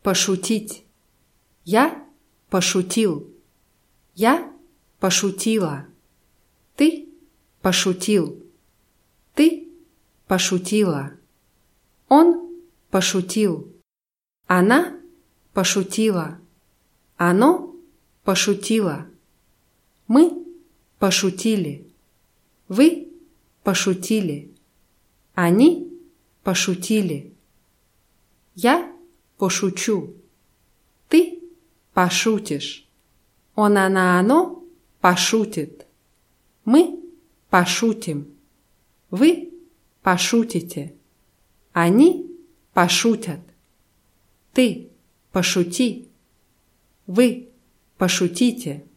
пошутить [paschutʲítʲ]